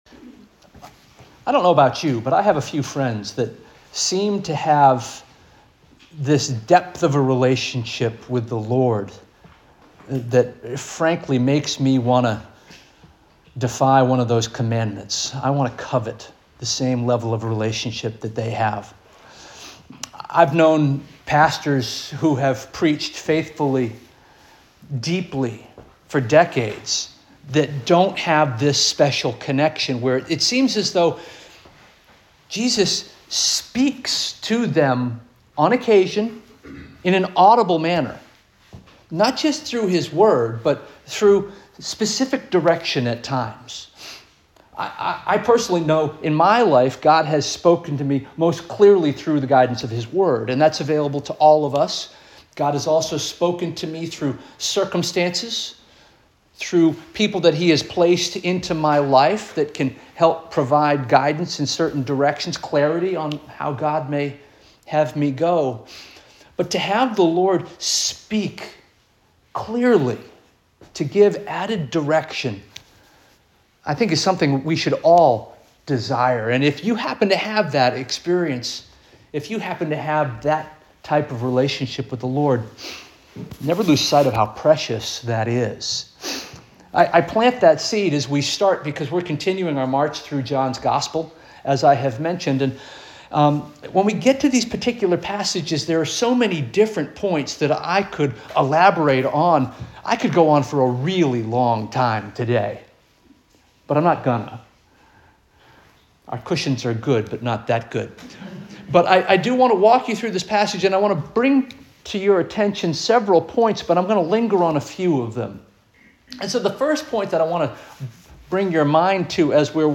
November 2 2025 Sermon - First Union African Baptist Church